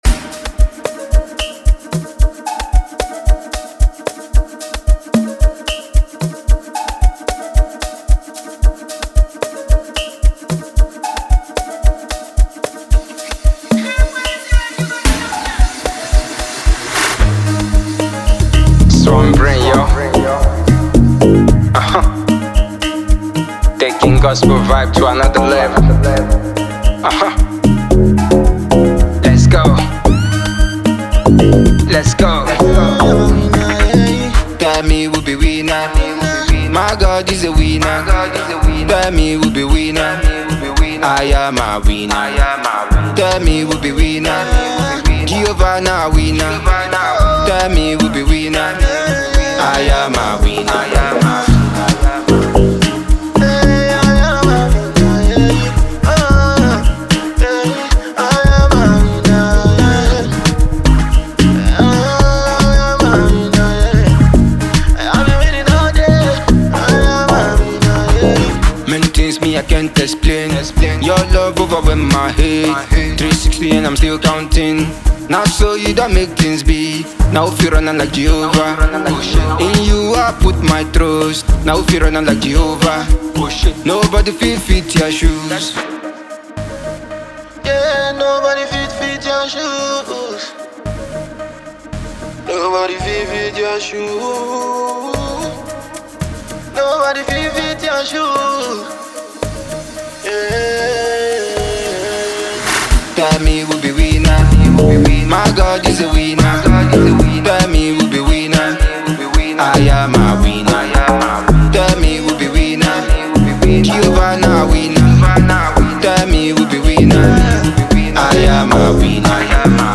contemporary Gospel afrobeat